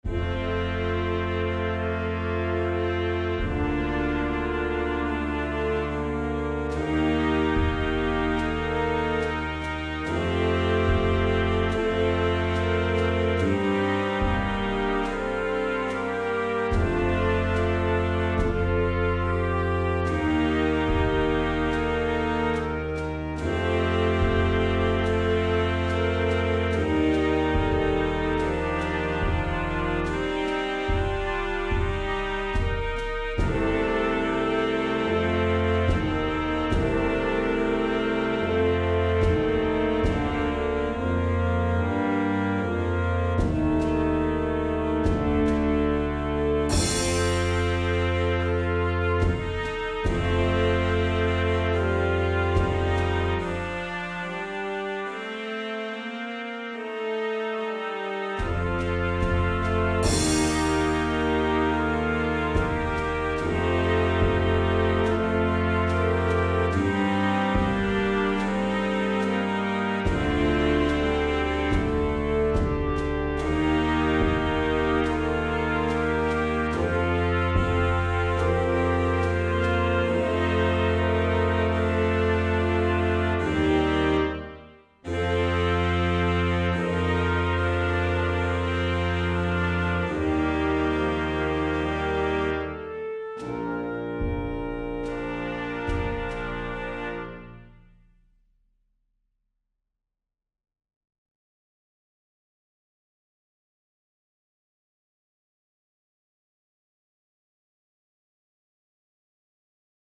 Flex Concert Band
Part 1: Flute, Oboe, Clarinet
Part 2: Alto Sax, Clarinet, Trumpet
Part 3: Clarinet, Alto sax, F horn
Part 4: Bassoon, Trombone, Euphonium, Tuba
Percussion: Snare Drum, Bass Drum, Crash Cymbal